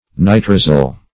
Search Result for " nitrosyl" : The Collaborative International Dictionary of English v.0.48: Nitrosyl \Ni*tro"syl\, n. [Nitroso- + -yl.]